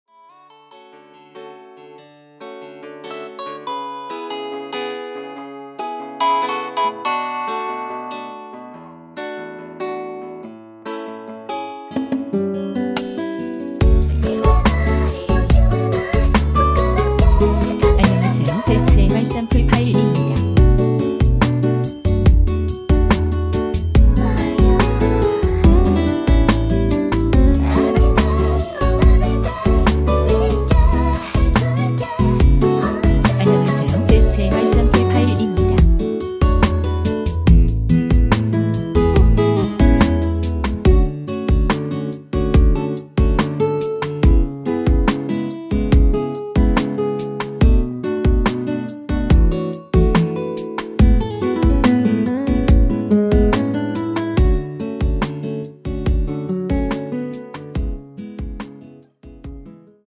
미리듣기에서 나오는 부분이 이곡의 코러스 전부 입니다.(원곡에 코러스가 다른 부분은 없습니다.)
원키 코러스 포함된 MR입니다.(미리듣기 확인)
앞부분30초, 뒷부분30초씩 편집해서 올려 드리고 있습니다.